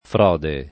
fr0de] s. f. — ant. froda [fr0da] e fraude [fr#ude]: quella sozza imagine di froda [kU%lla S1ZZa im#Jine di fr0da] (Dante); vincere o per forza o per fraude [